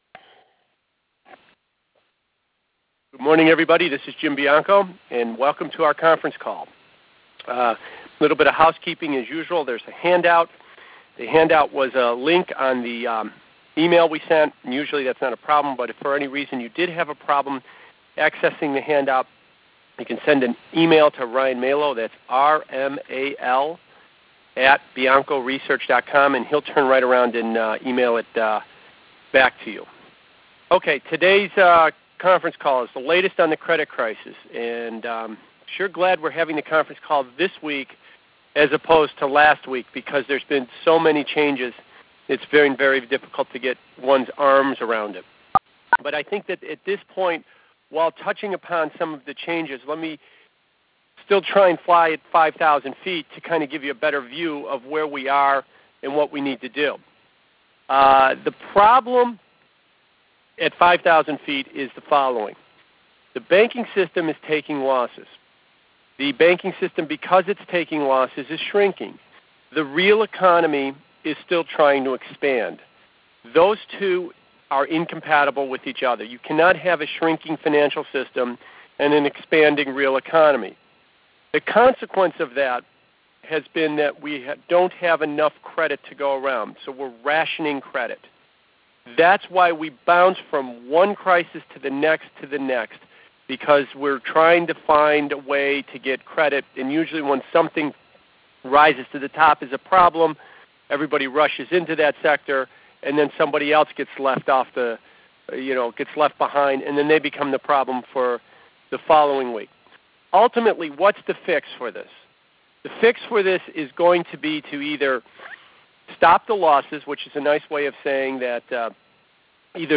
The Latest On The Credit Crisis Audio Link of Conference Call A transcript of the March 20, 2008 conference call.